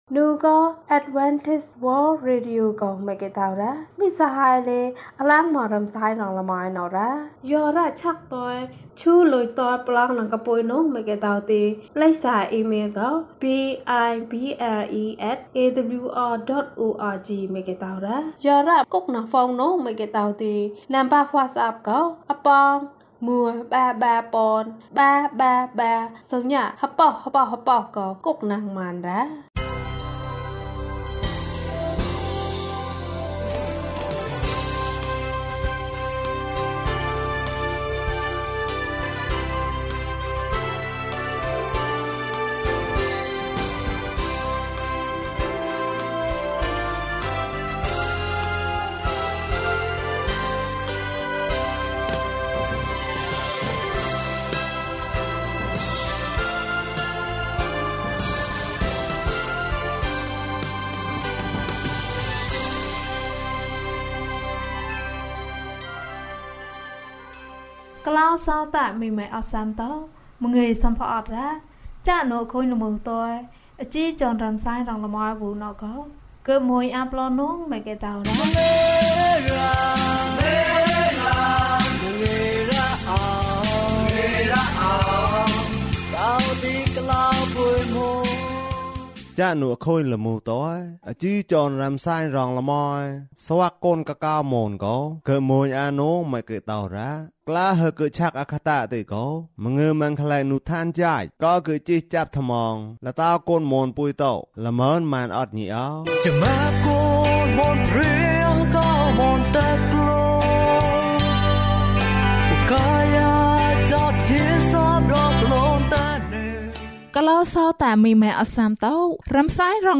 ဘုရားသခင်သည် ချစ်ခြင်းမေတ္တာဖြစ်သည်။၀၃ ကျန်းမာခြင်းအကြောင်းအရာ။ ဓမ္မသီချင်း။ တရားဒေသနာ။